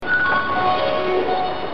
Before some announcements, a jingle is played (
jinglenewratp.mp3